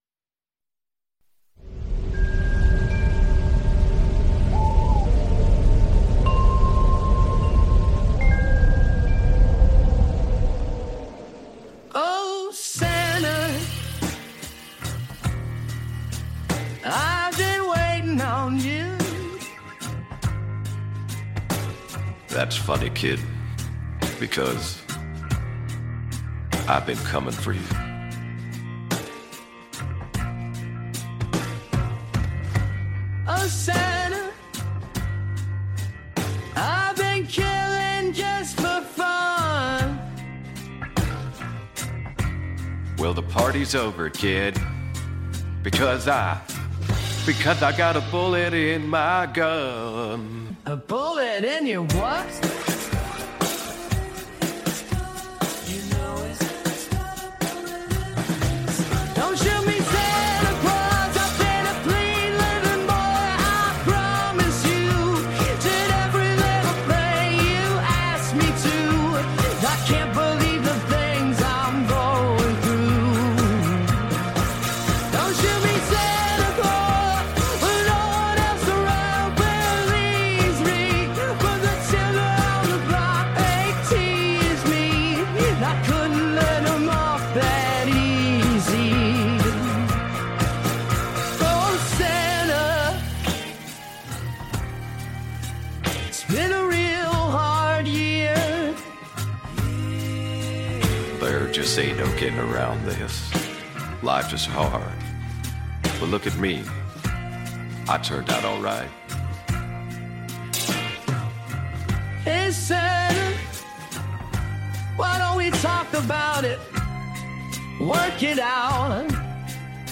insolito e scanzonato
unusual and lighthearted song